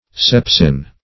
Search Result for " sepsin" : The Collaborative International Dictionary of English v.0.48: Sepsin \Sep"sin\, n. [Gr.
sepsin.mp3